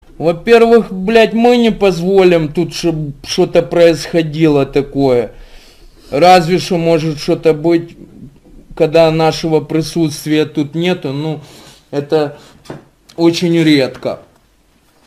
• Качество: 192, Stereo
голосовые